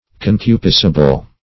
concupiscible - definition of concupiscible - synonyms, pronunciation, spelling from Free Dictionary
Concupiscible \Con*cu`pis*ci*ble\, a. [Cf. F. concupiscible.]